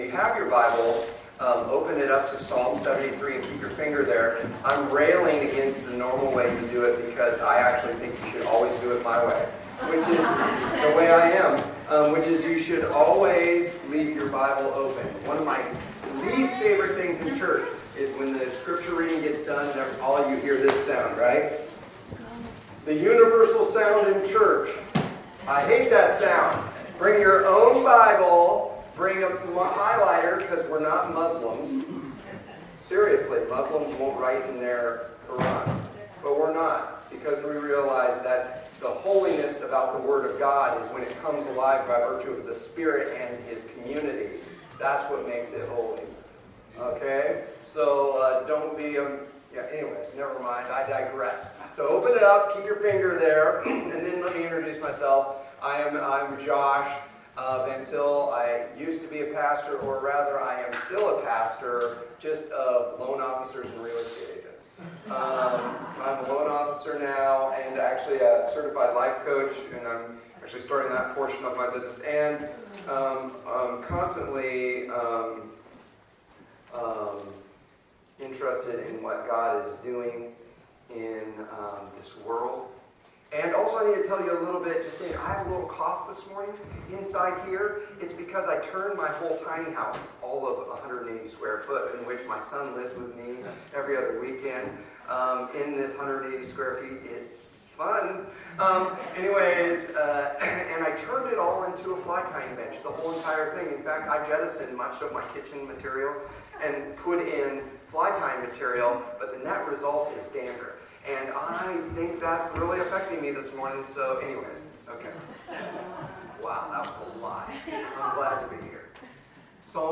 Passage: Psalm 73 Service Type: Sunday Morning